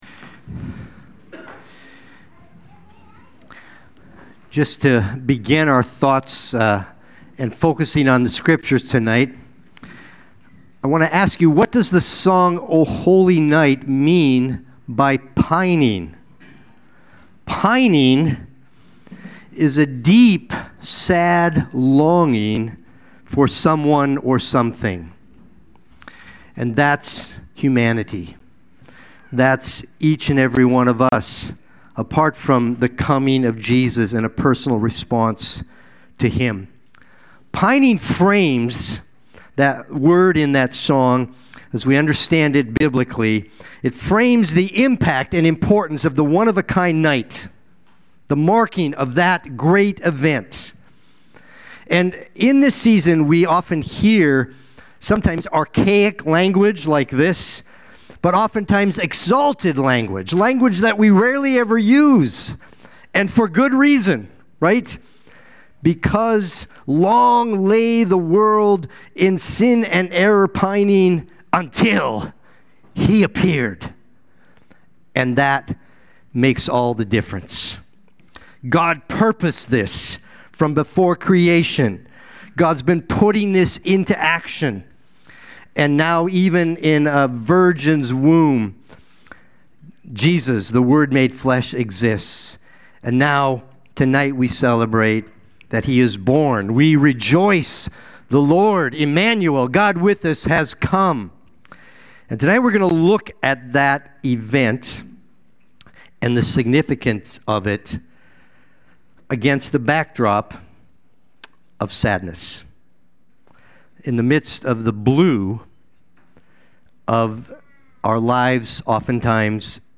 Christmas Eve Worship Service – 12/24/25
Watch the replay or listen to the sermon.